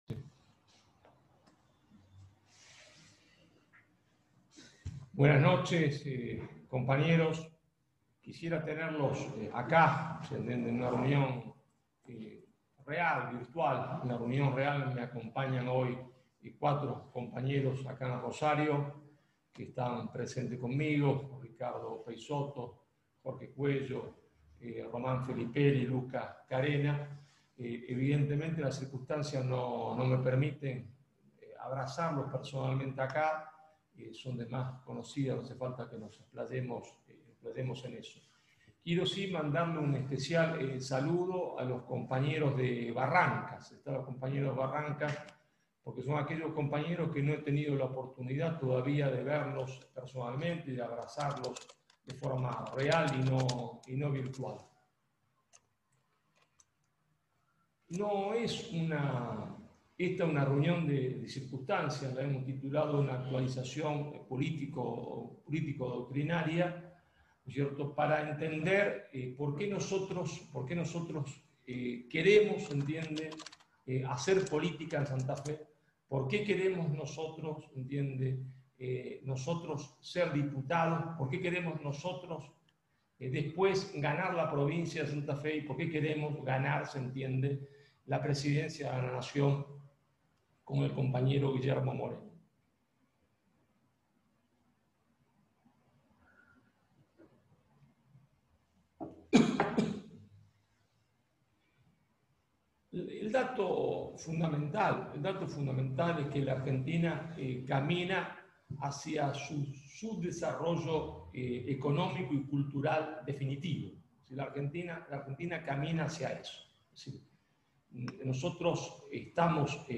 Encuentro virtual de formación doctrinaria a cargo del Prof. Marcelo Gullo.